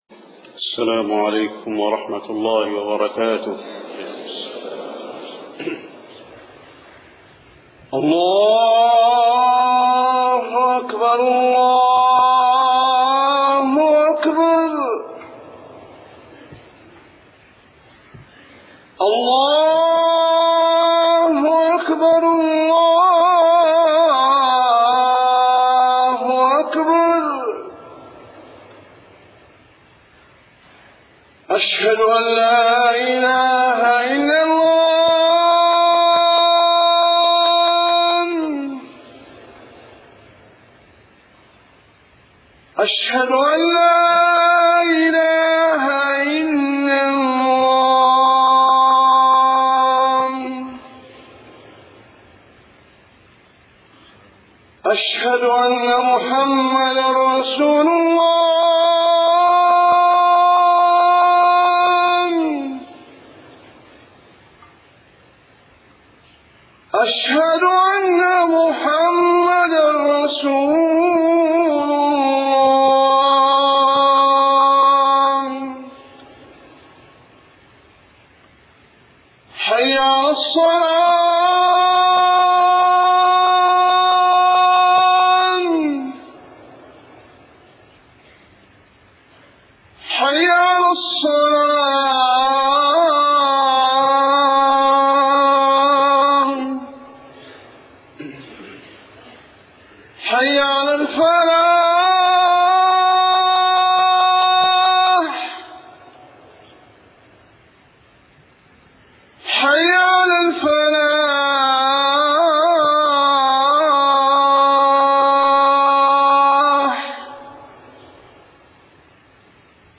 خطبة الجمعة 21 صفر 1431هـ > خطب الحرم النبوي عام 1431 🕌 > خطب الحرم النبوي 🕌 > المزيد - تلاوات الحرمين